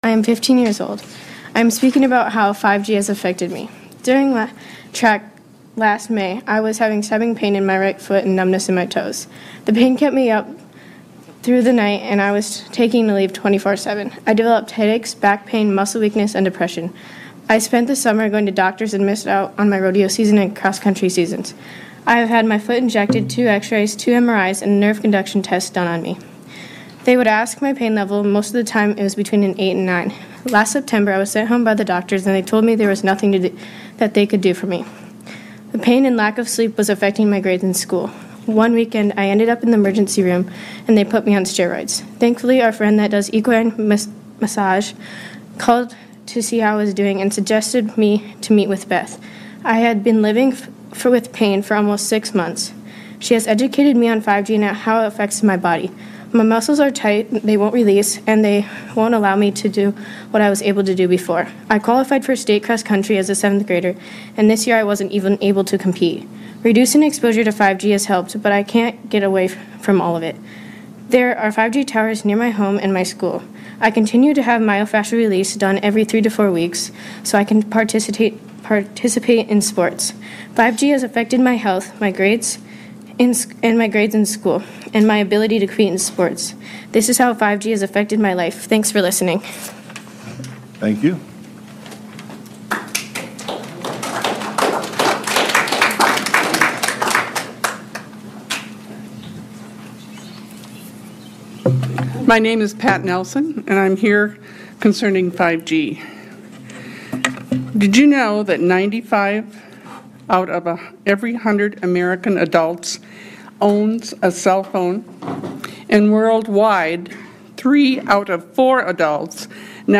5G Health Effects Testimony to Mitchell South Dakota Council May 2022